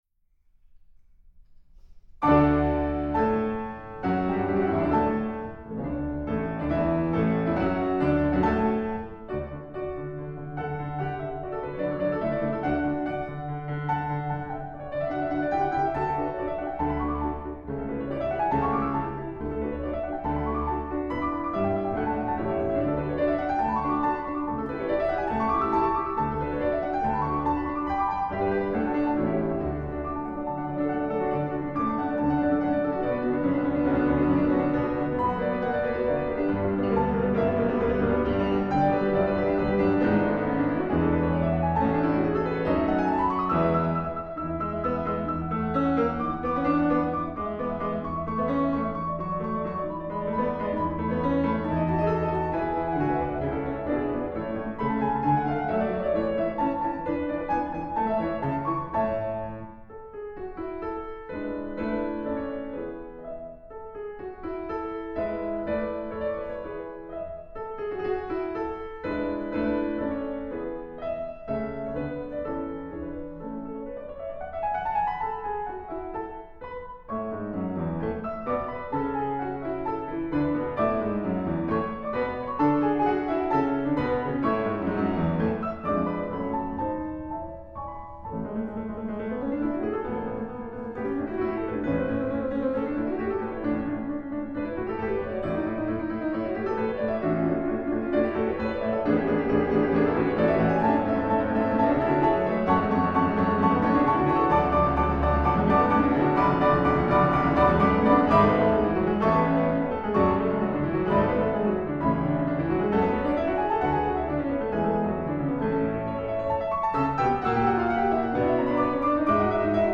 A sampling of my solo and chamber music recordings:
Mozart Sonata for Two Pianos in D Major, K 448 – Movement I